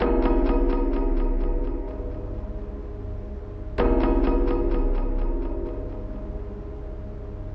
描述：My dog chewing on a raquetball.
标签： rubberball weird pop rubber creepy dog squeek ball
声道立体声